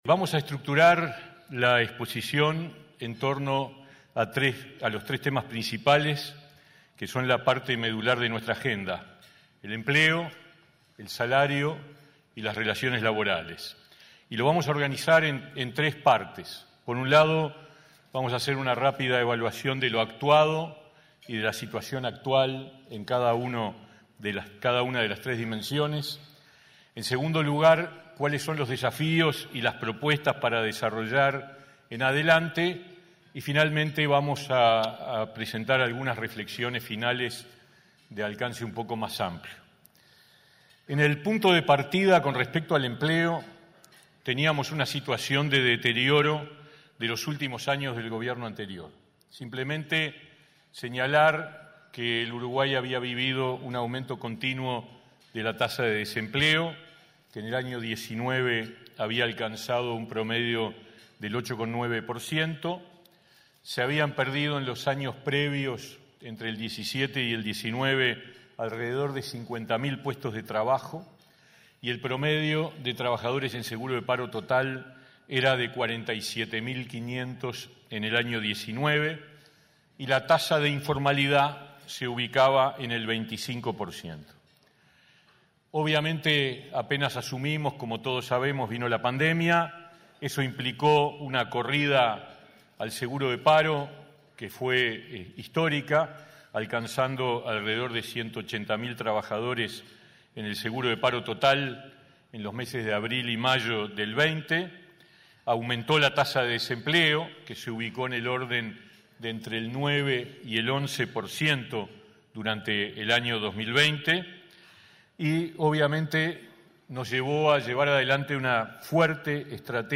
Este 29 de junio, el ministro Pablo Mieres disertó en un almuerzo de la Asociación de Dirigentes de Marketing del Uruguay (ADM).